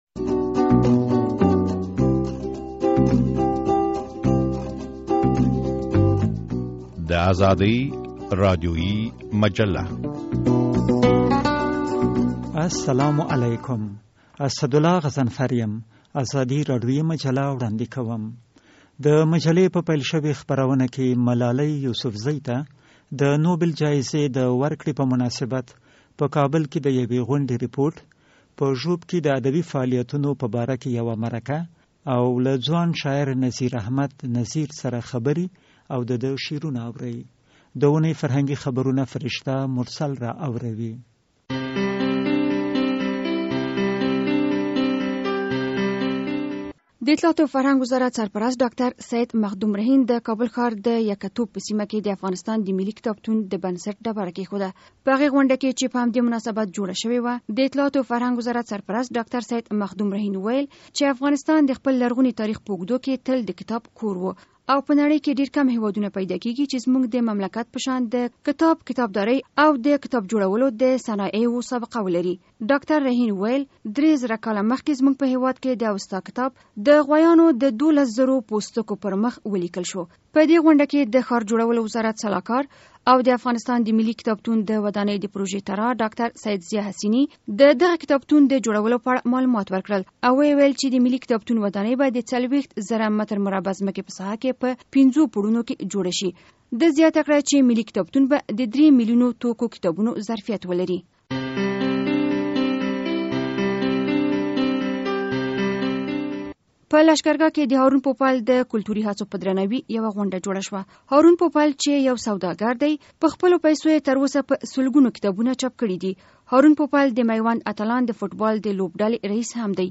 د ازادي راډیویي مجلې په دې خپروونه کې په کابل کې د ملالۍ یوسفزۍ ته د نوبل جایزې د ورکړې په مناسبت د یوې غونډې رپورټ اورئ.